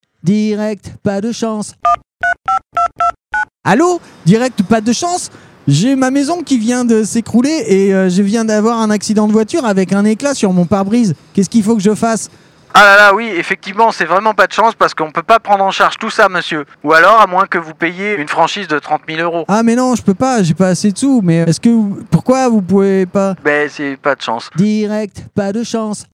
Fausses Pubs RADAR